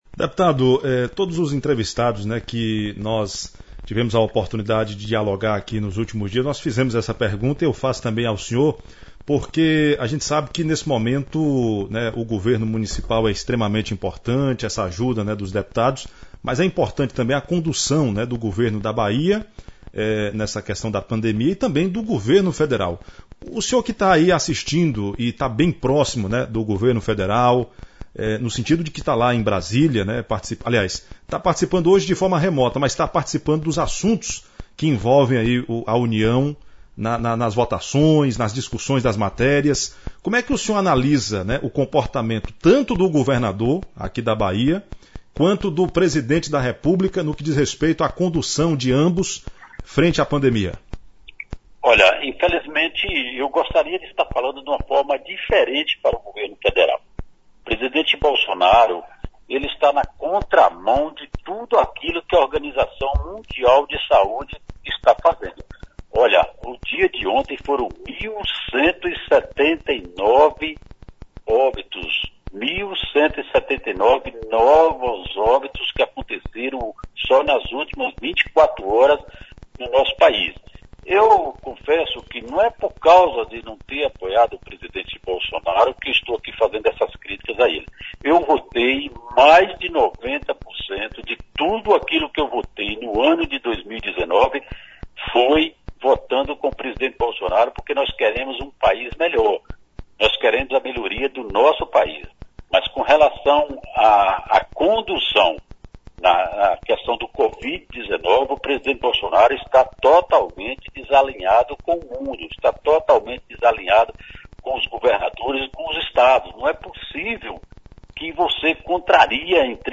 O deputado federal Charles Fernandes (PSD) falou nesta quarta-feira (20), sobre as ações do seu mandato no combate à pandemia do novo coronavírus, durante entrevista concedida ao Programa Giro de Notícias, da Rádio Visão FM de Palmas de Monte Alto.